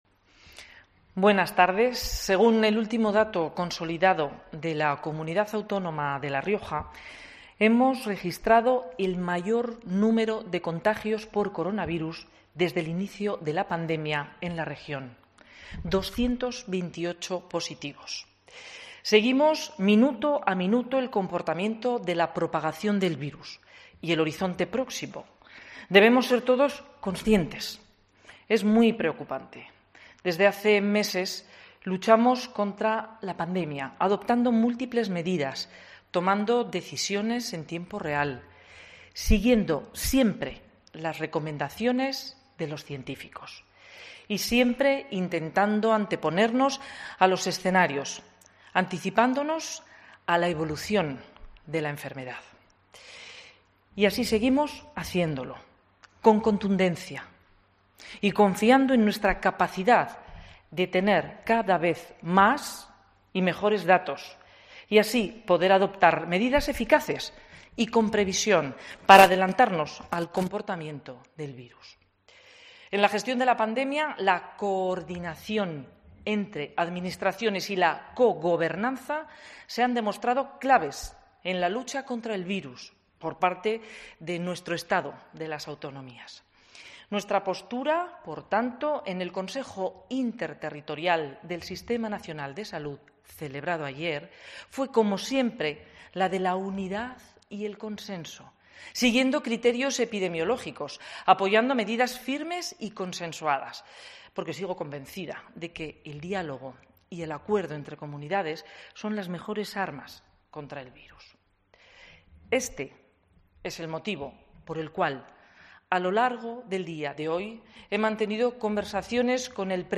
Lo ha hecho a través del siguiente comunicado:
Solicitud del estado de alarma para La Rioja por parte de la presidenta Andreu